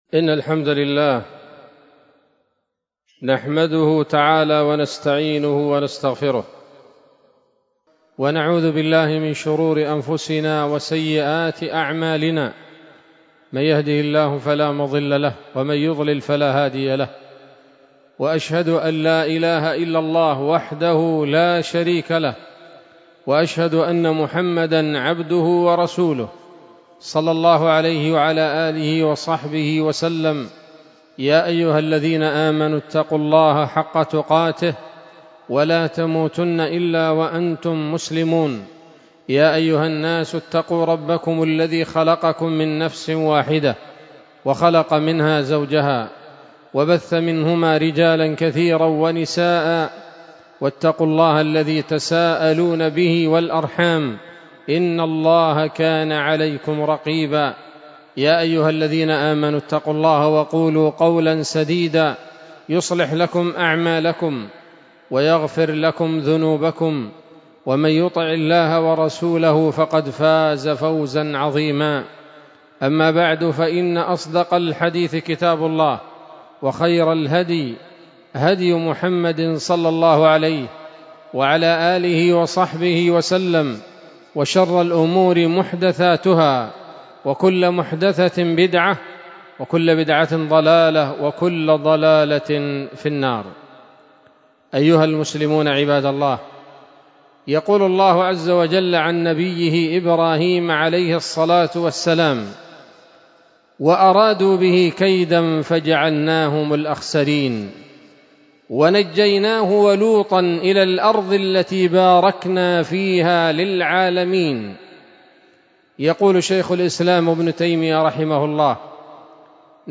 خطبة جمعة بعنوان: (( الشام وتكالب الأعداء )) 12 جمادى الآخرة 1446 هـ، دار الحديث السلفية بصلاح الدين